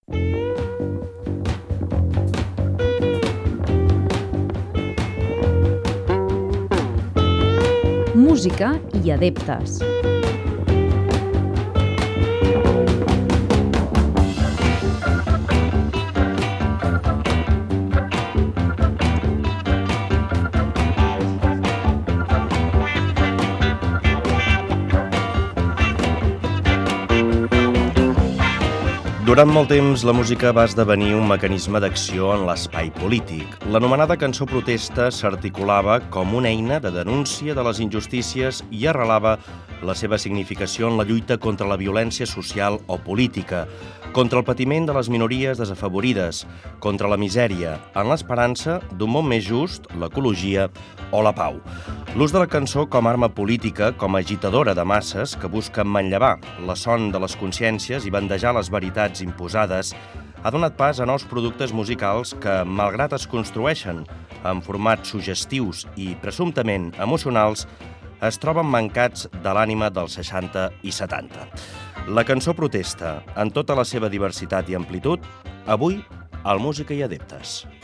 Careta i inici del programa de músiques de cancó protesta
Musical